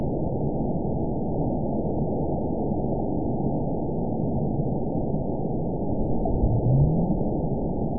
event 914022 date 04/26/22 time 02:01:33 GMT (3 years ago) score 9.69 location TSS-AB01 detected by nrw target species NRW annotations +NRW Spectrogram: Frequency (kHz) vs. Time (s) audio not available .wav